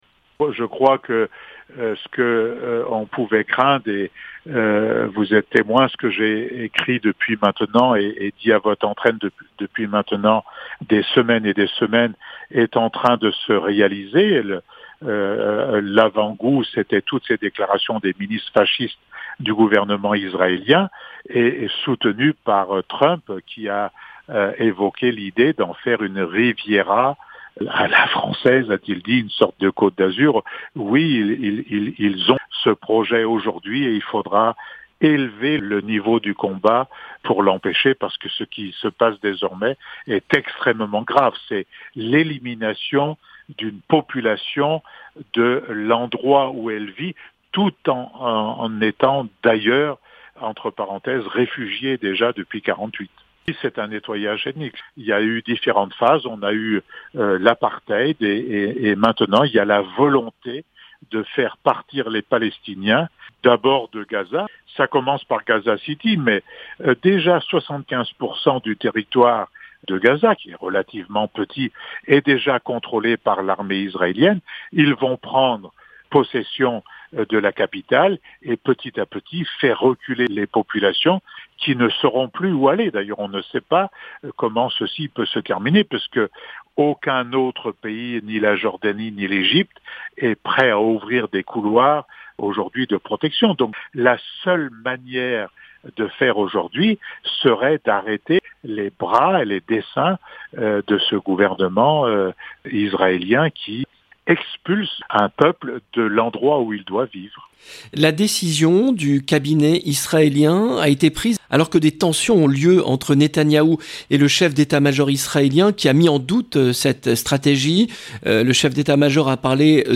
Entretien avec Patrick Le Hyaric